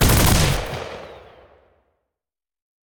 CosmicRageSounds / ogg / general / combat / weapons / mgun / fire2.ogg
fire2.ogg